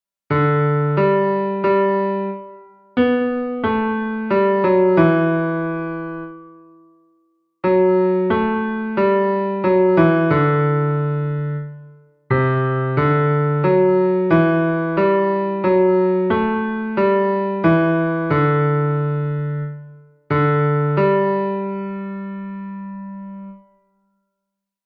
Look at the image and listen to the sound which shows the melody of this F-clef exercise.